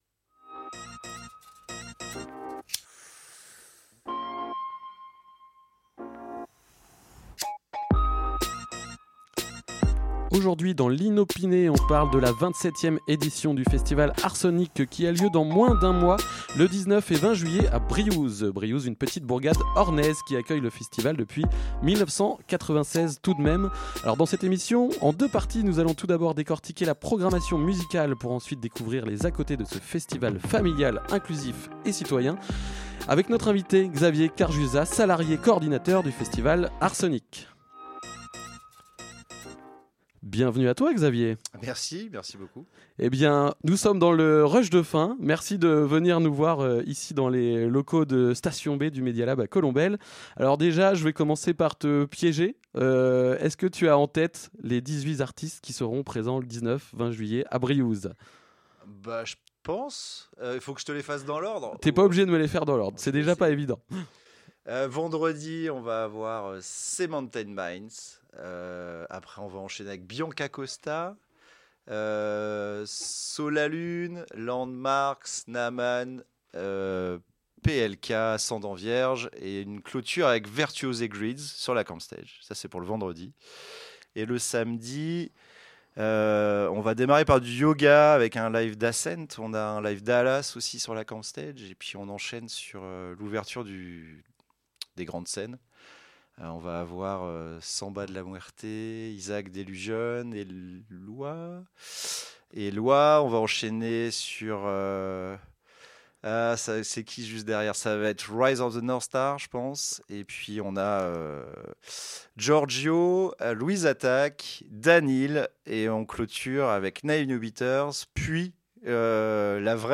Radio Pulse 90.0FM à Alençon